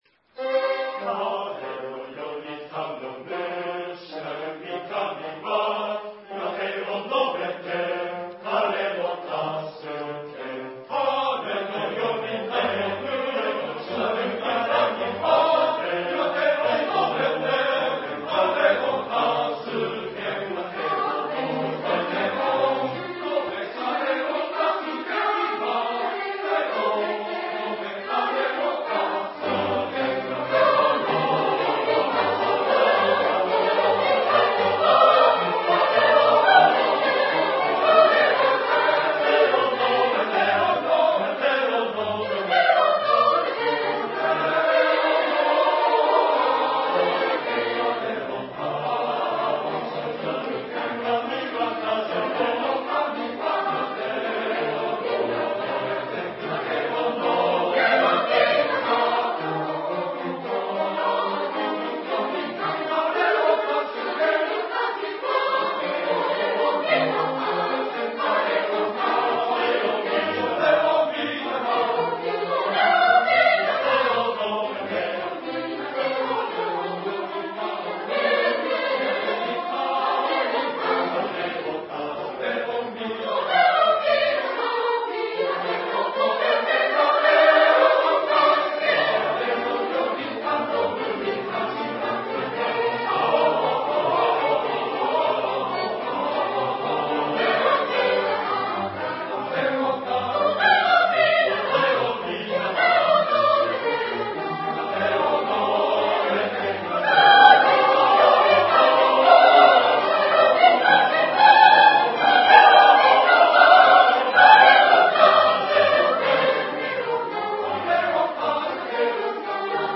吉祥寺2002年公演録音